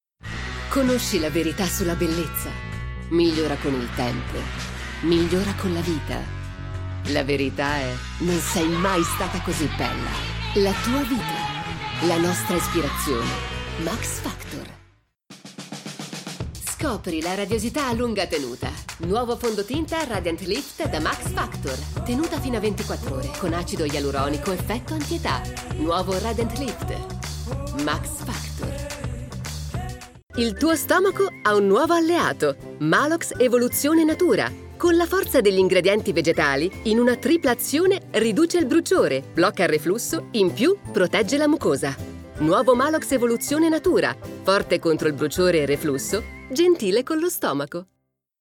Voice Talent, Speaker, Script Translator and Adaptor, Actress, Teacher
Sprechprobe: Werbung (Muttersprache):
I have a soundproof home studio with professional sound absorber panels, Rode NT USB microphone and Vocal Booth Kaotica Eyeball